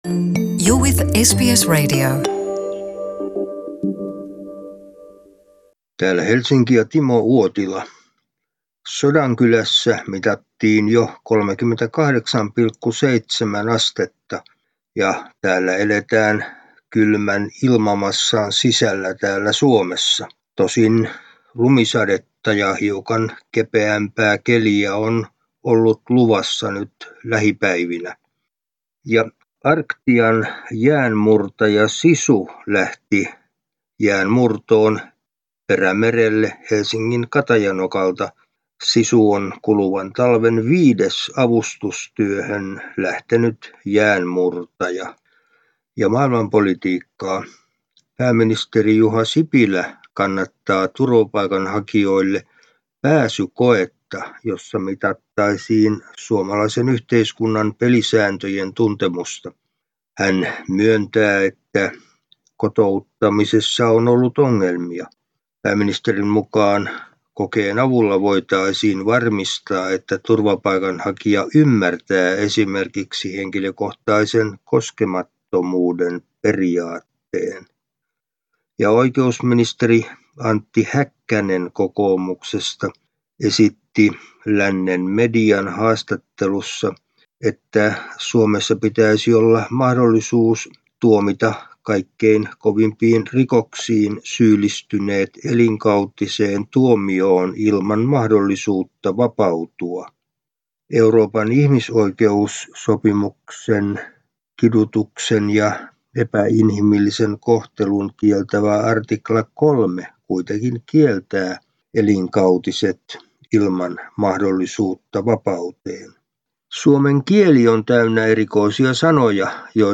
ajankohtaisraportti